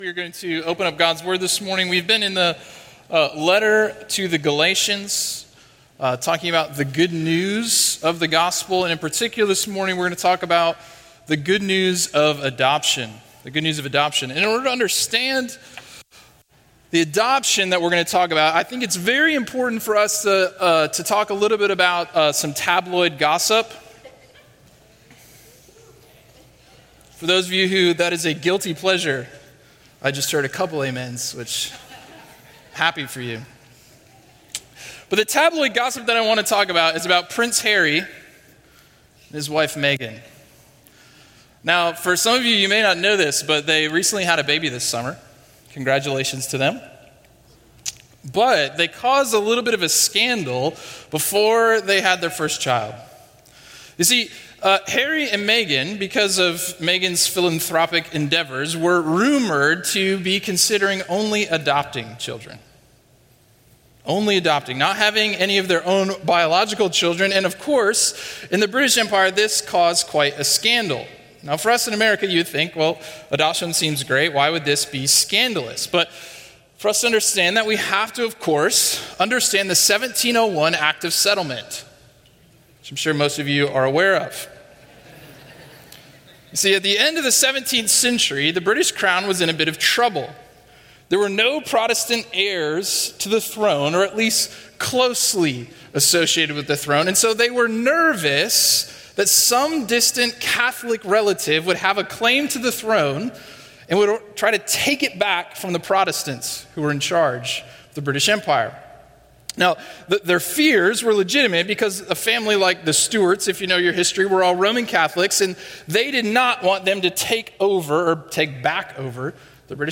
A sermon series on Galatians by Crossway Community Church in Charlotte, NC.